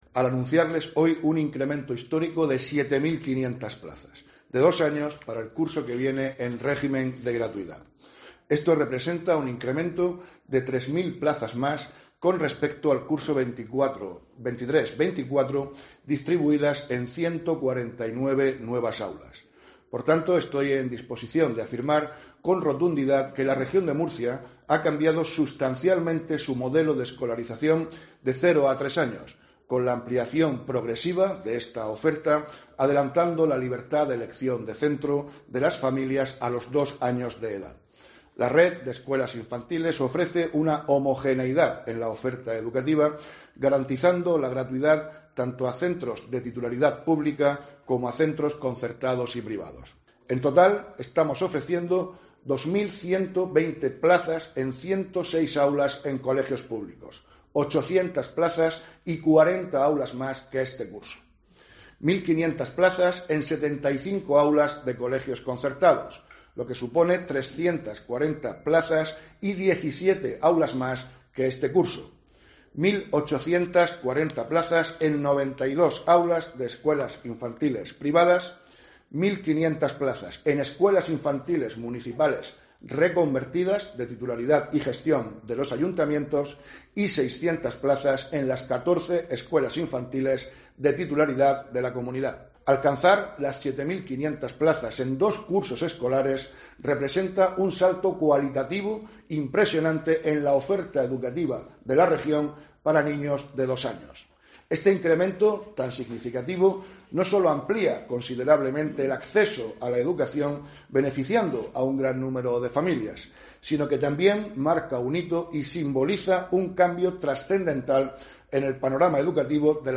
Víctor Marín, consejero de Educación, Formación Profesional y Empleo